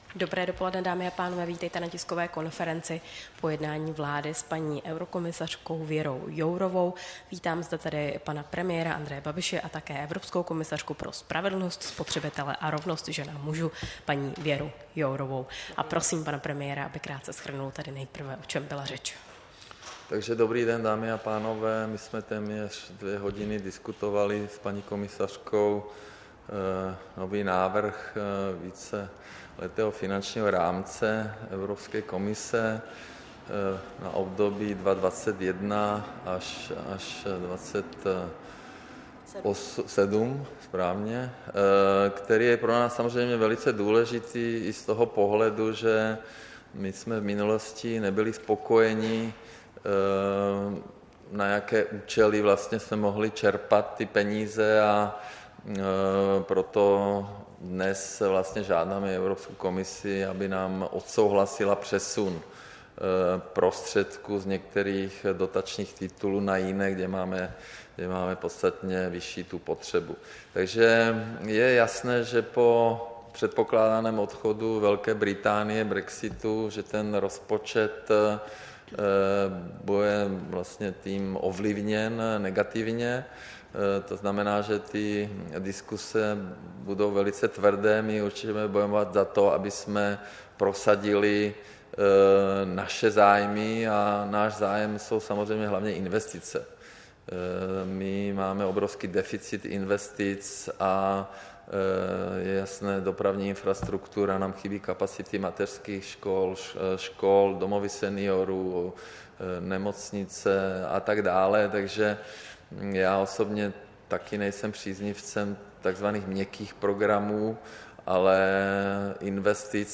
Tisková konference za účasti eurokomisařky Věry Jourové, 9. května 2018